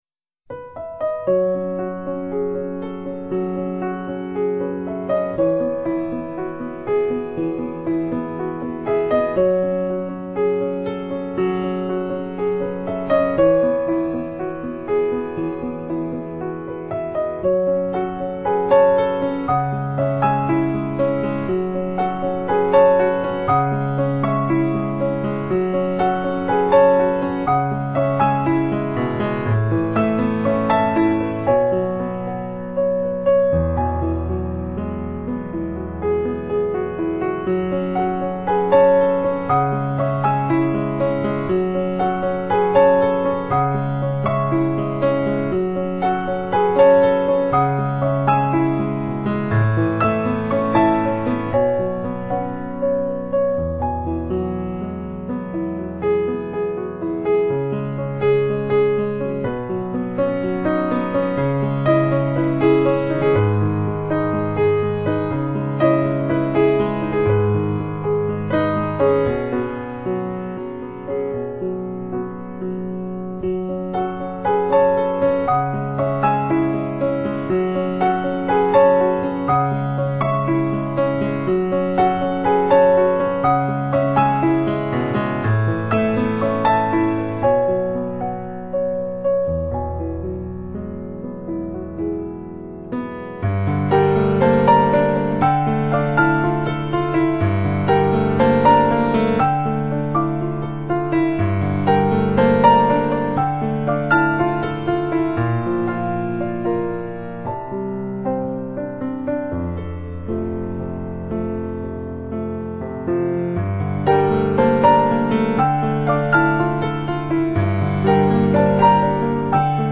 آهنگ عاشقانه و زیبا از پیانیست با سابقه امریکایی
نوع آهنگ: لایت]